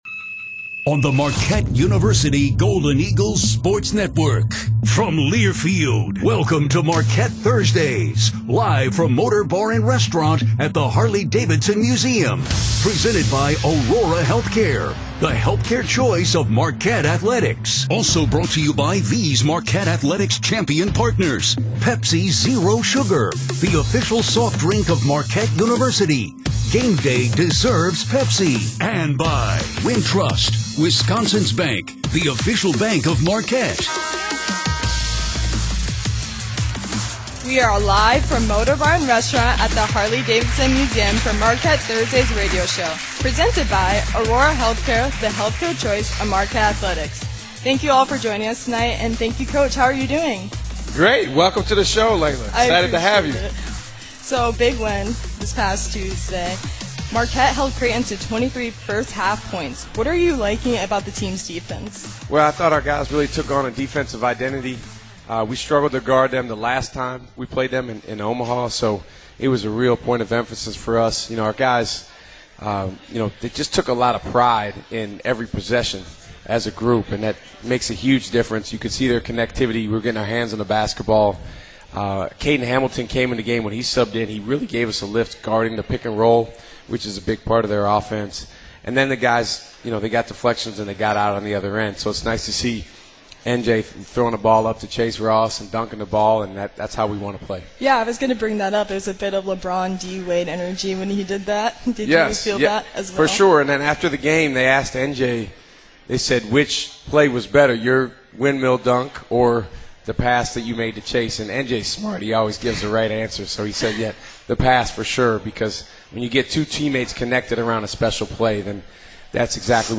All content from the Marquette Radio Network on 94.5 ESPN Milwaukee.